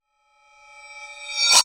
time_warp_reverse_high_04.wav